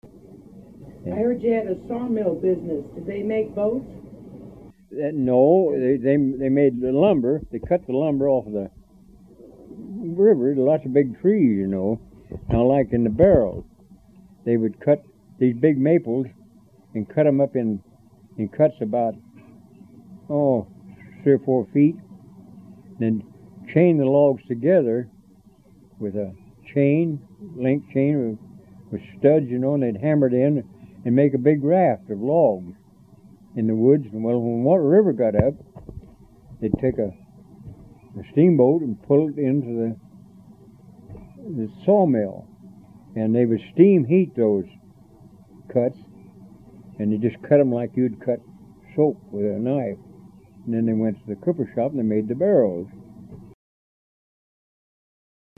HTR Oral History, 07/17/1